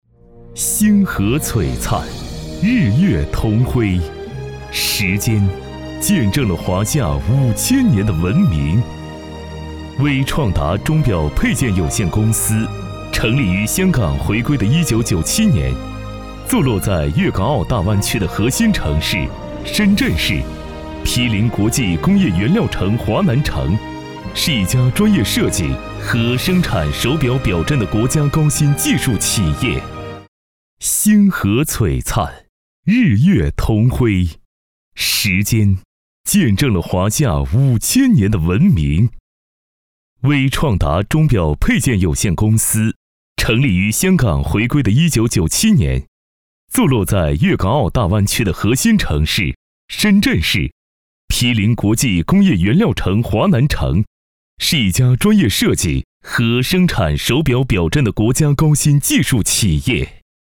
男39号